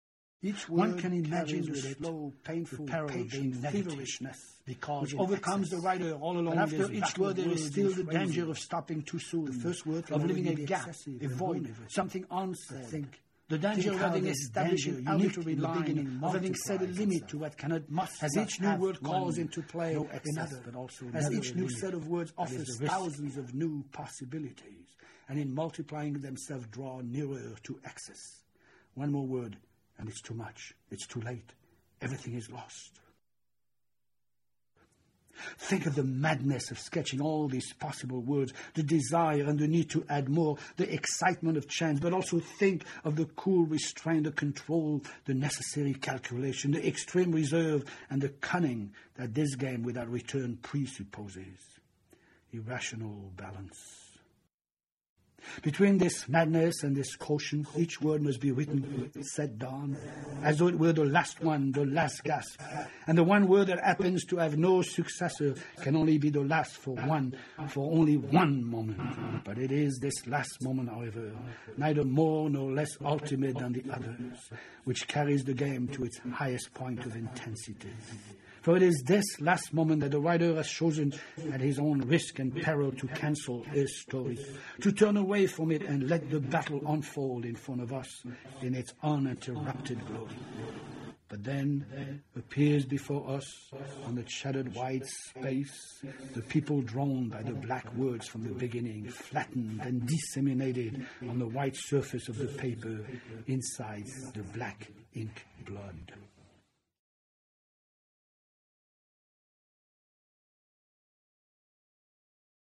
TAKE IT OR LEAVE IT is an audio production of three major chapters from the story. It is created entirely from electronic modified bits and pieces of Federman's voice, and further exagerates.
Note: Due to length of time all but one of the recordings is in mpeg3 mono.